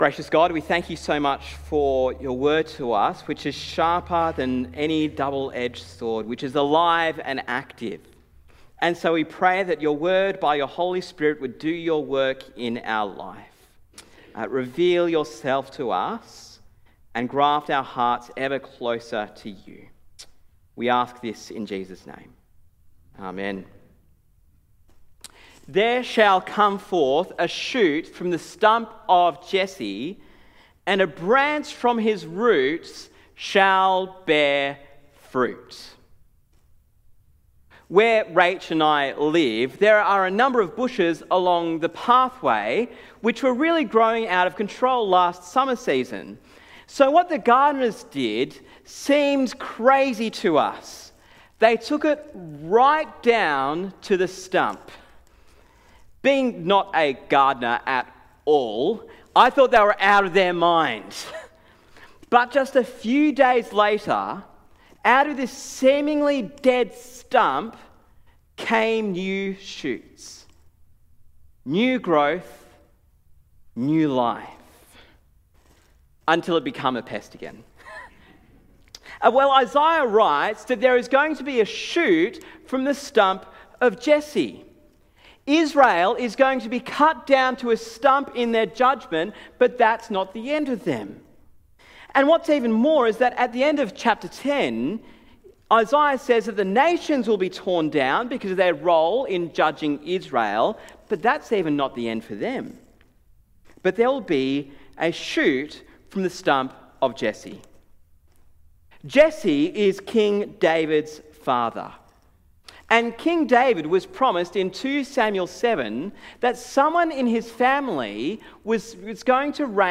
Listen to the sermon on Isaiah 11 in our Isaiah series.